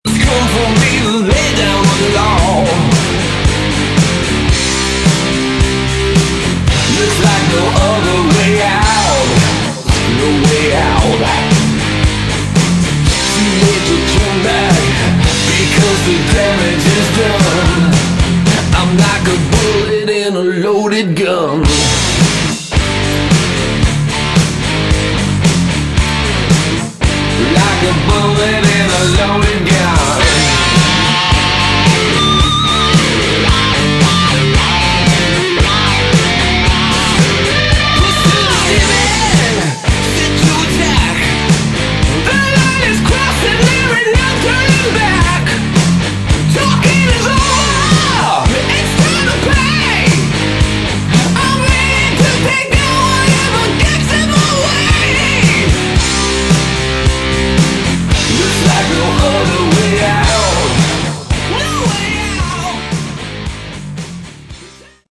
Category: Hard Rock
Vocals, Guitar
Vocals, Bass
Drums
guitar, keyboards